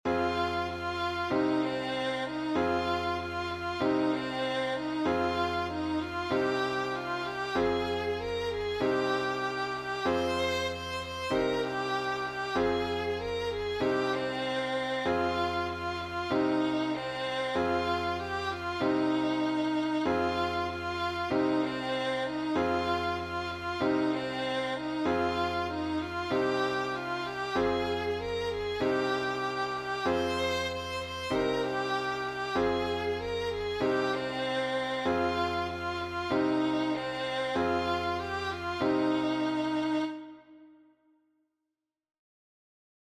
Contemporary English Quaker Round